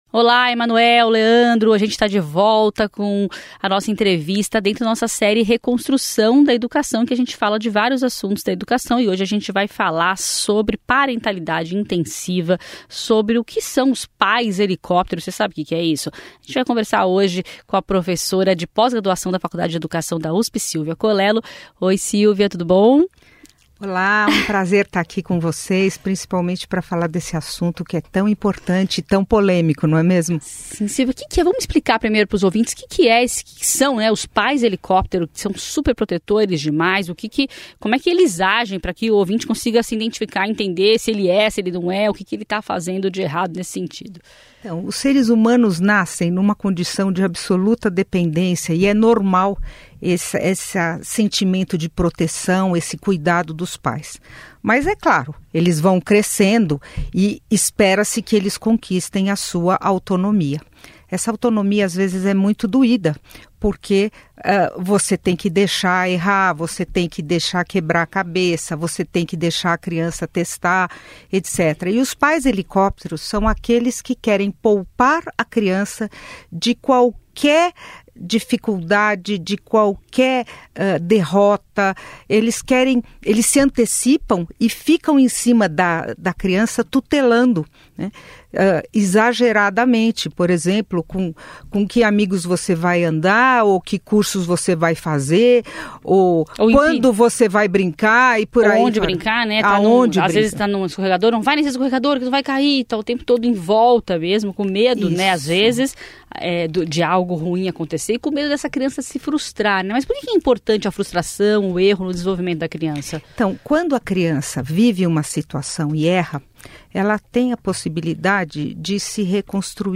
entrevista na íntegra